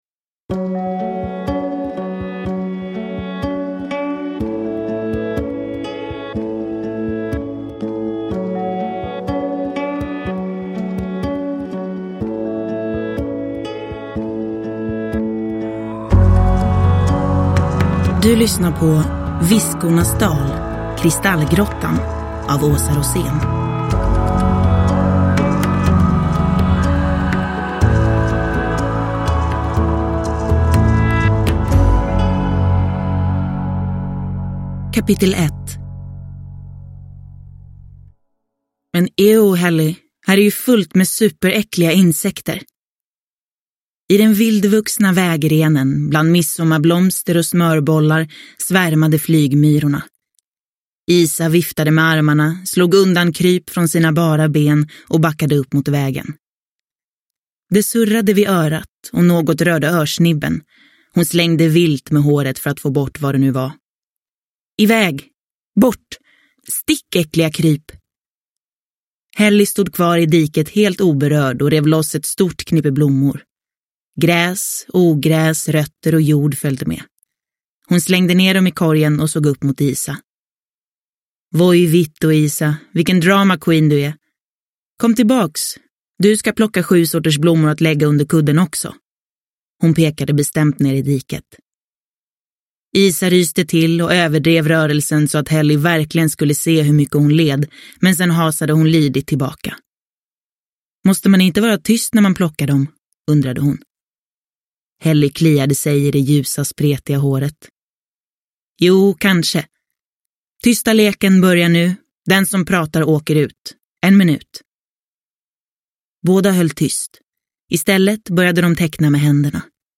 Kristallgrottan – Ljudbok